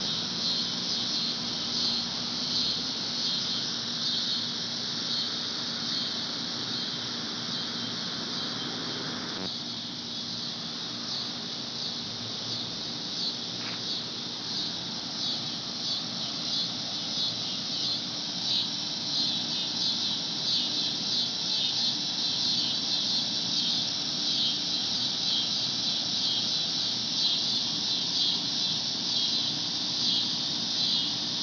Here is what they sound and look like:
Cicadas.wav